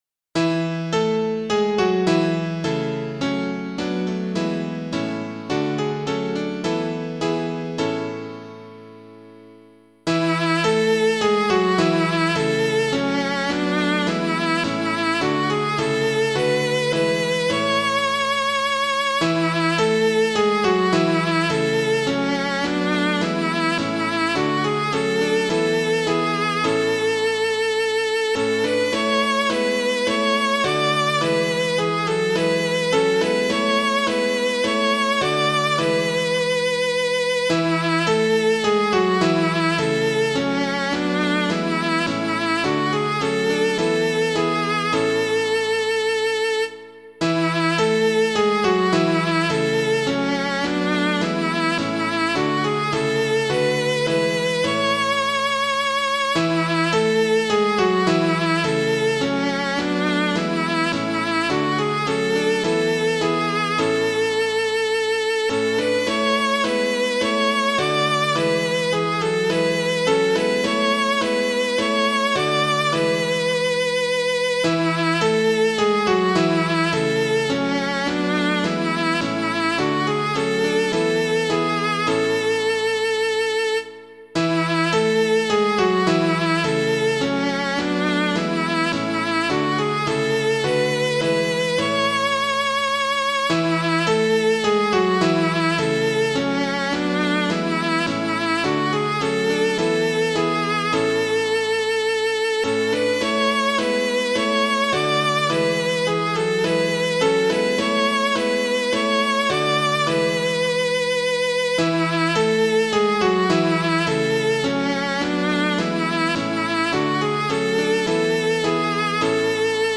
I Sing the Mighty Power of God [Watts - ELLACOMBE] - piano.mp3